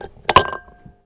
gear_on.wav